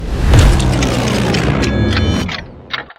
Loud Za Warudo